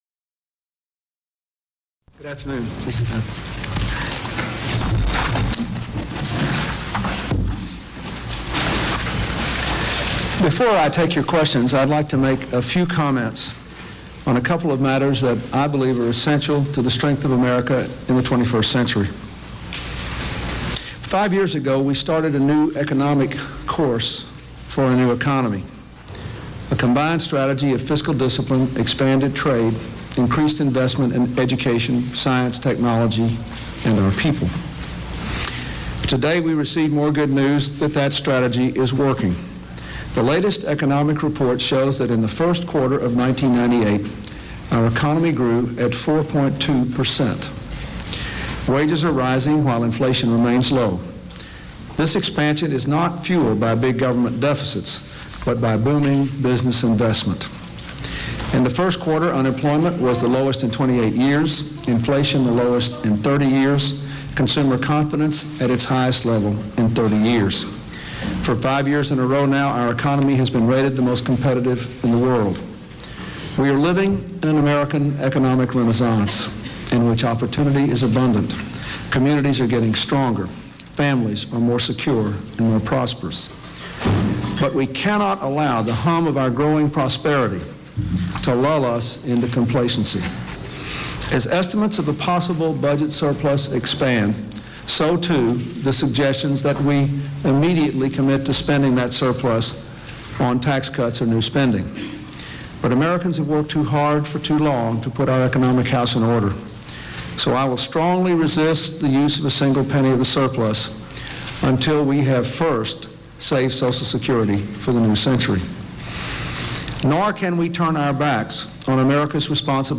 U.S. President Bill Clinton holds a press conference on the U.S. economic condition